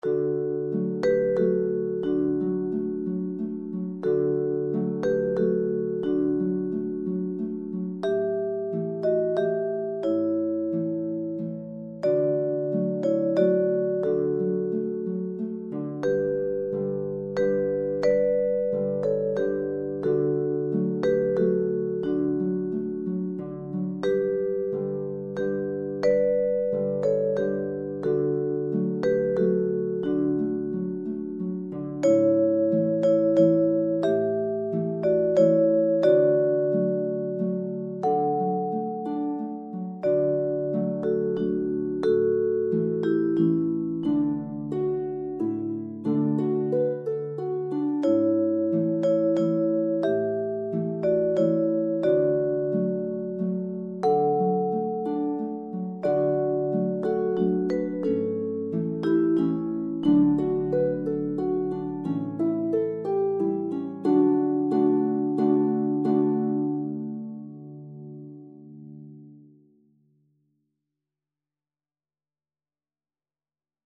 Met de originele muziek van de componist